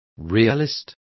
Complete with pronunciation of the translation of realist.